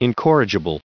added pronounciation and merriam webster audio
421_incorrigible.ogg